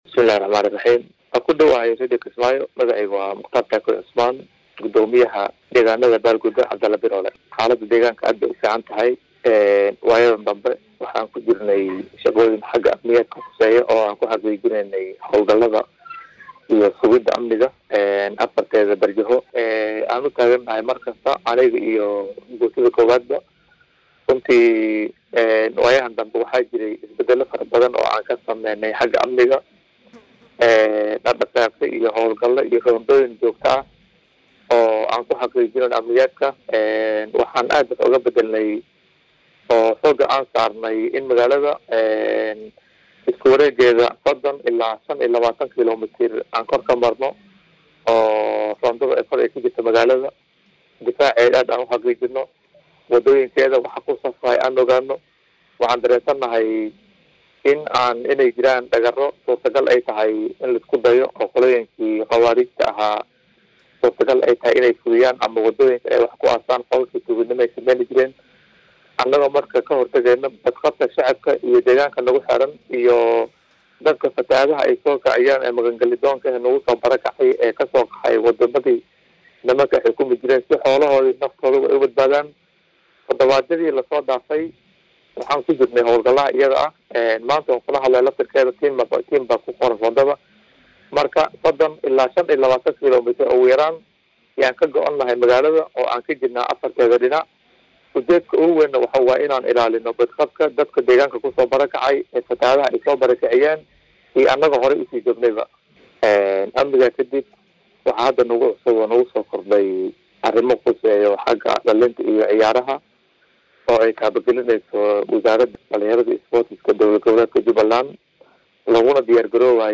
Gudoomiyaha Deegaanka Cabdulle Biroole Muqtaar Taakooy Cismaan Oo Wareesi Gaar ah Siiyay Radio Kismaayo ayaa Ka Warbixiyay Xaalada Amniga Deegaanka isagoona Sheegay in Maalmihii udanbeeyay Howlgalo Amni Xaqiijin ah ay Ka Wadeen Deegaanada Hoosyimaada Deegaanka Cabdulle Biroole.